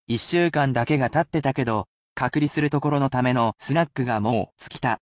I will read them aloud as many times as you wish, but if you are a beginner, there is no need to repeat, as it is meant to be at full speed in order to help you pick out the vocabulary words in everyday speech.